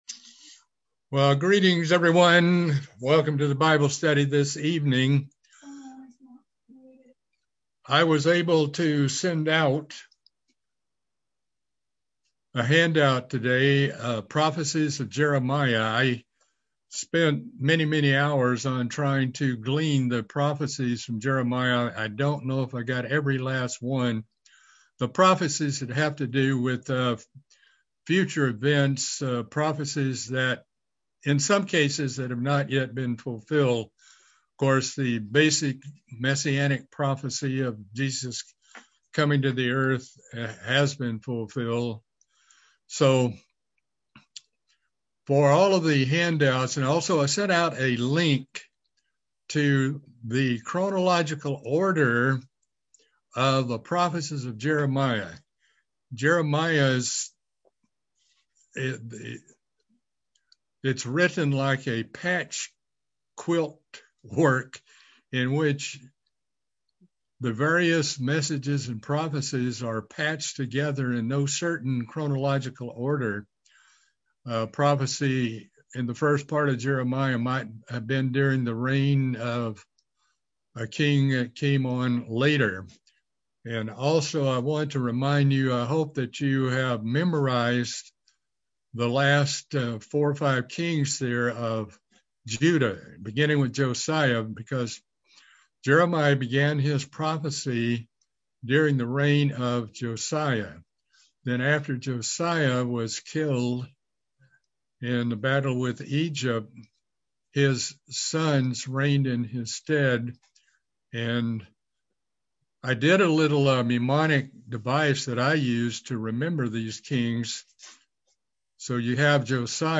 Part 12 of a Bible Study series on the book of Jeremiah